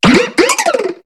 Cri de Maracachi dans Pokémon HOME.